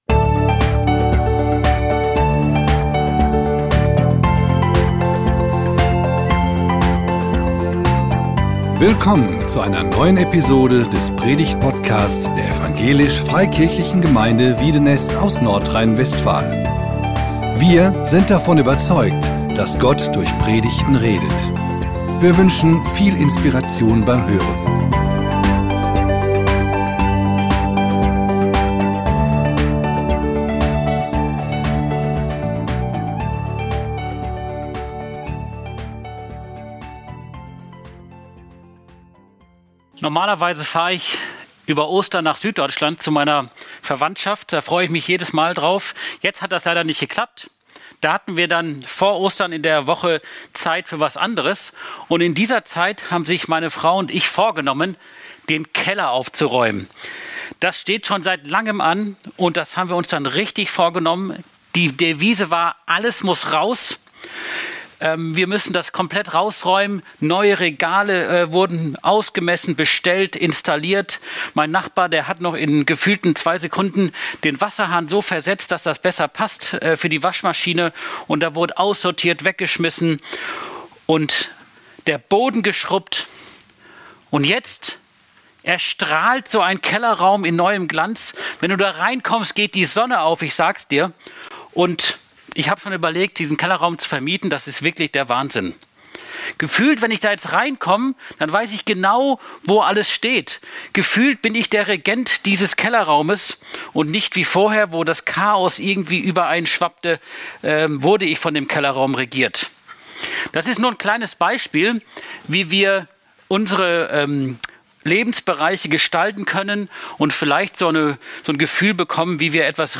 Leben: Regiert! - Predigt